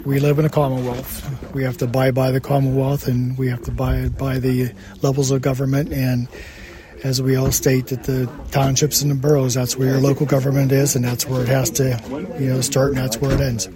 County Commissioner Mike Keith was in attendance, and he said the residents can only change their supervisors by voting.